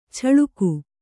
♪ chaḷuku